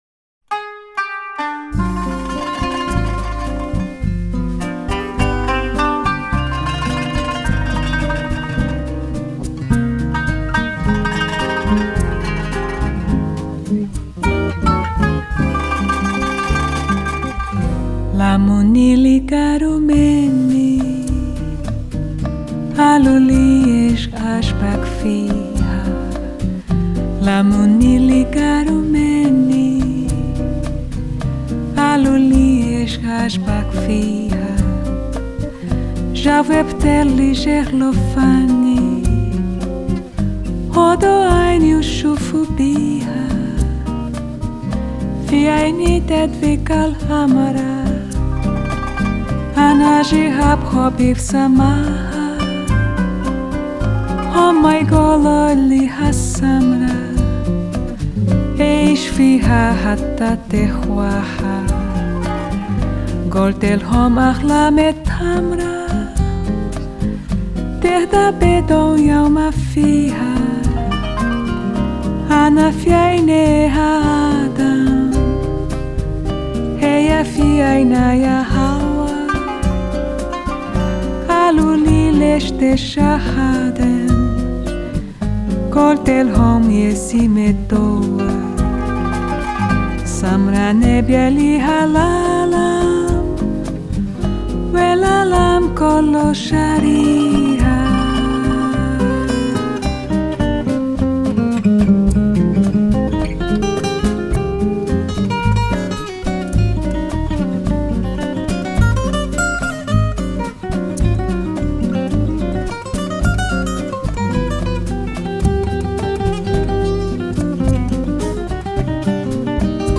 閒適怡人、無拘無束的音樂，搭配上她時而慵懶柔軟、時而活潑俏皮的唱腔，
音樂類型：爵士樂 - Bossa Nova[center]